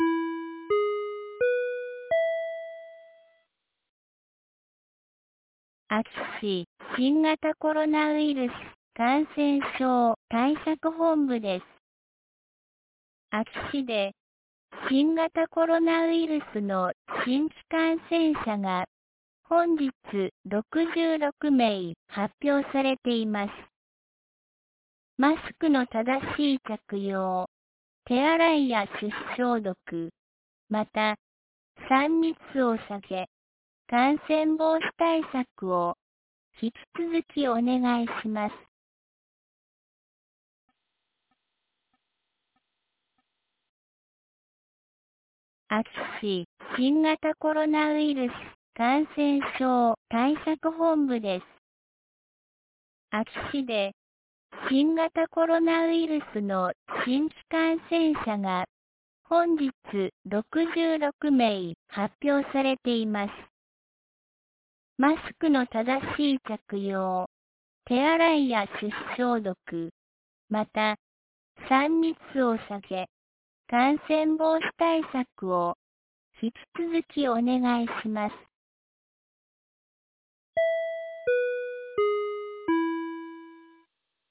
2022年08月10日 17時06分に、安芸市より全地区へ放送がありました。